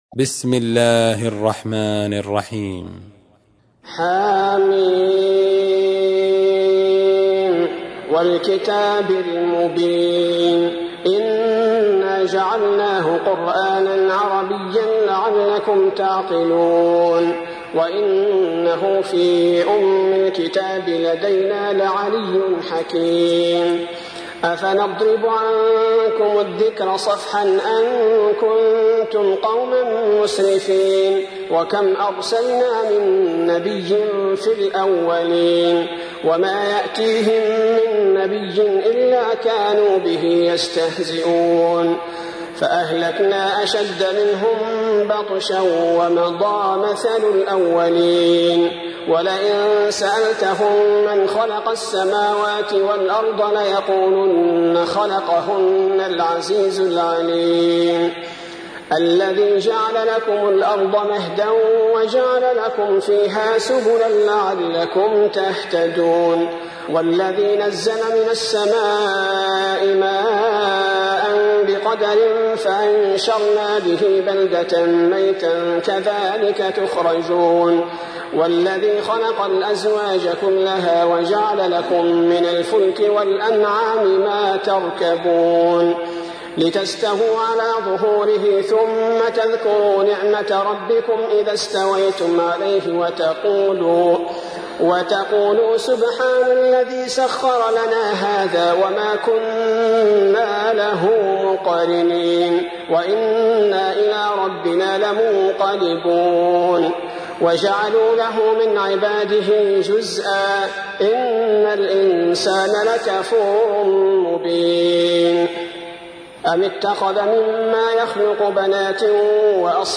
تحميل : 43. سورة الزخرف / القارئ عبد البارئ الثبيتي / القرآن الكريم / موقع يا حسين